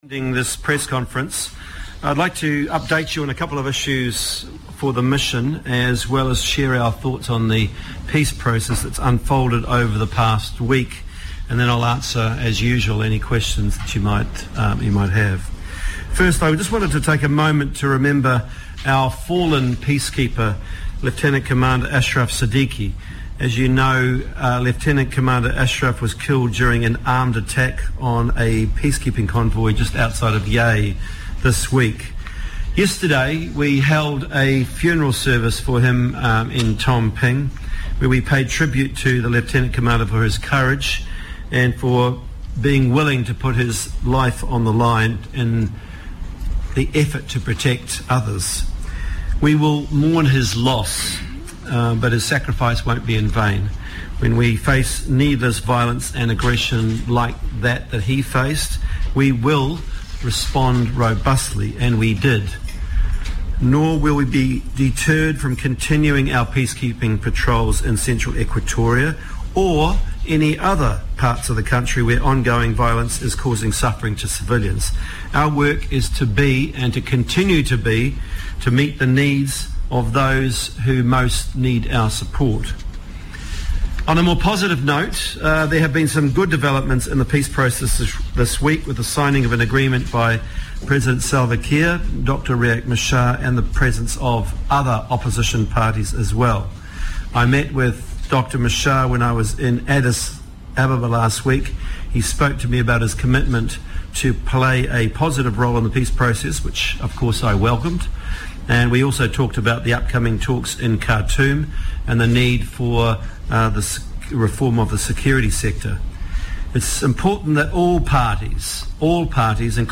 SRSG David Shearer addressed News Conference in Juba following attack on Peacekeeper
Addressing a press briefing in Juba this morning, Special Representative and head of UNMISS, David Shearer said the UN would consider further action on the international forum to hold the perpetrators to account.